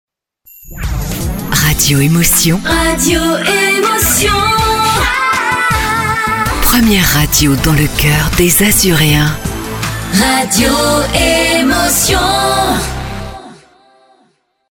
Comédienne voix off féminine - adolescente, jeune, âge moyen, mûre...
Sprechprobe: Sonstiges (Muttersprache):